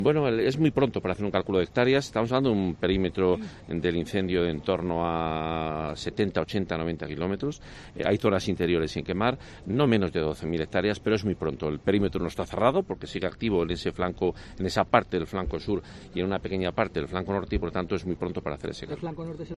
El consejero de Fomento y Medioambiente, Juan Carlos Suarez Quiñones, explicaba la situación actual del fuego.